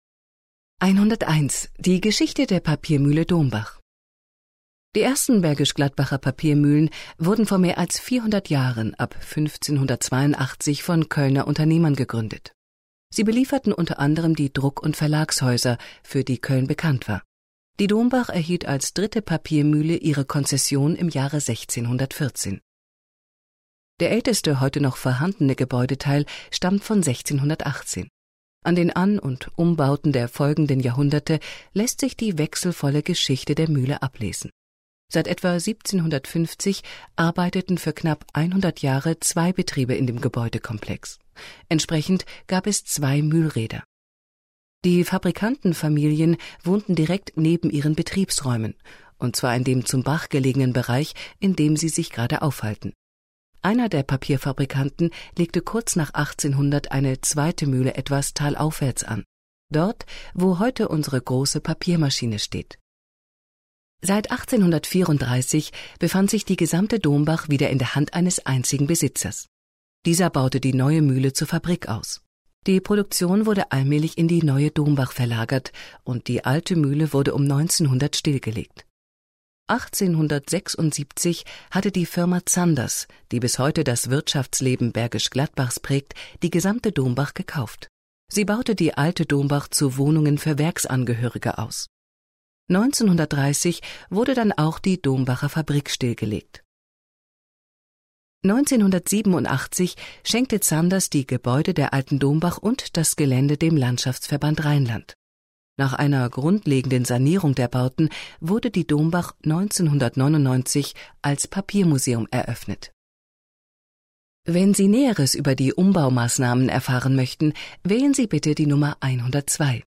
Audio-Rundgang
Beim Besuch können Sie einen Audio Guide ausleihen und die spannende Geschichte des Papiers als Hör-Information verfolgen.